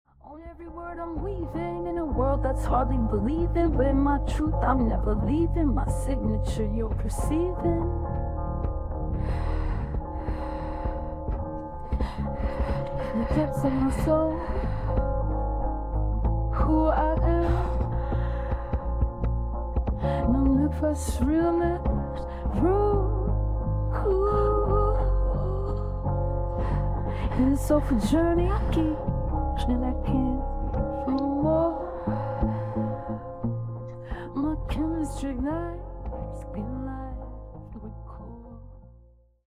Acoustic
An incredible Acoustic song, creative and inspiring.